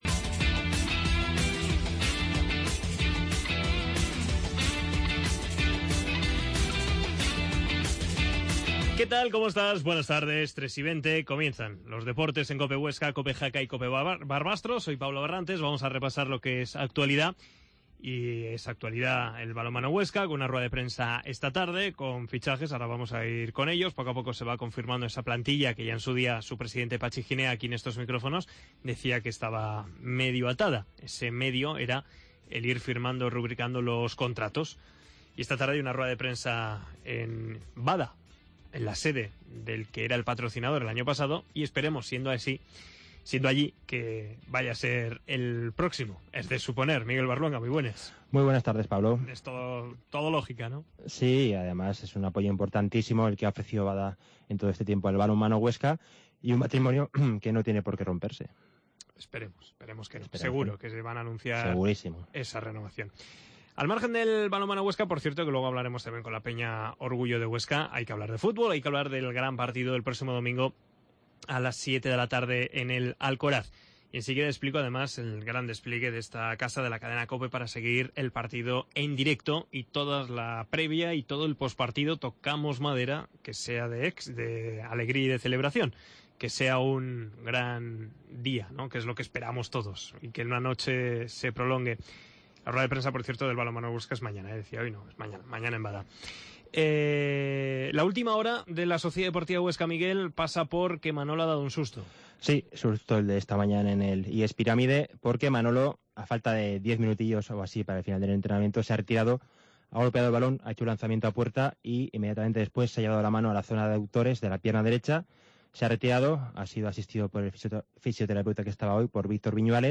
AUDIO: Entrevista
escuchámos al jugador del Huesca Fran Mérida